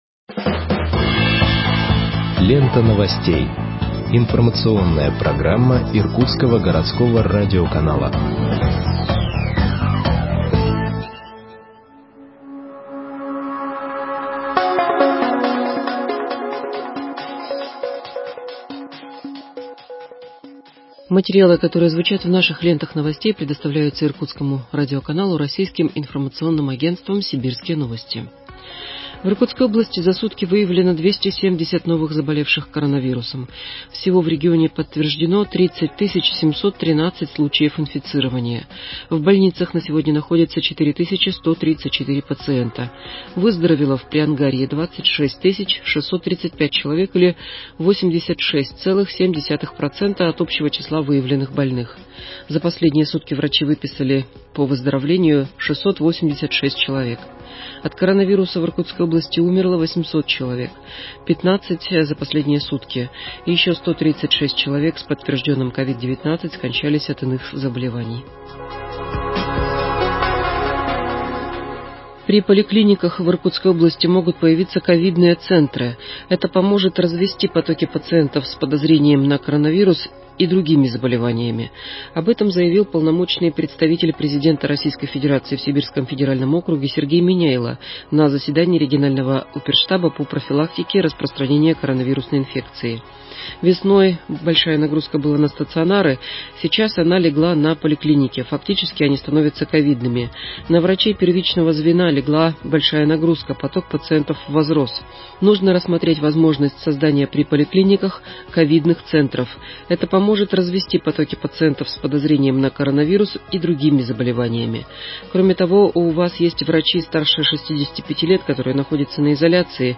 Выпуск новостей в подкастах газеты Иркутск от 25.11.2020 № 2